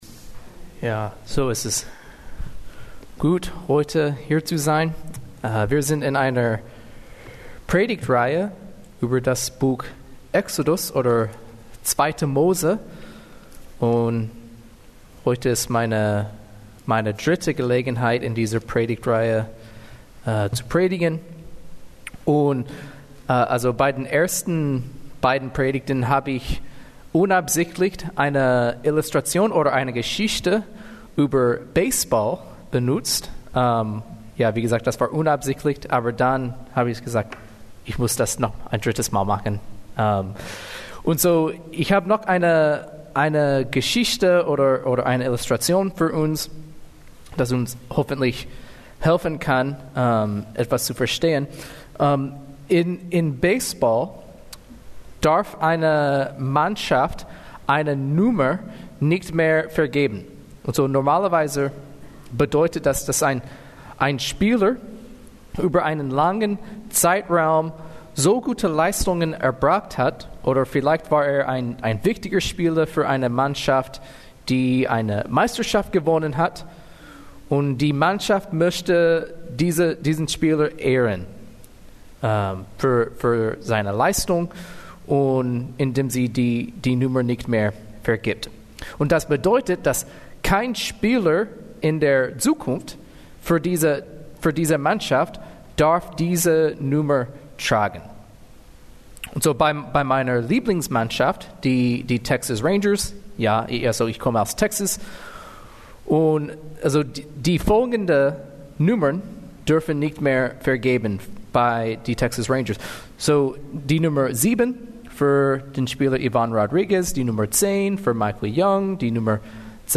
Mose 25-31 Kategorie: Gottesdienst « Gottes Bund mit seinem Volk (2.